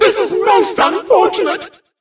bea_mon_die_vo_02.ogg